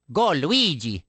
One of Luigi's voice clips from the Awards Ceremony in Mario Kart: Double Dash!!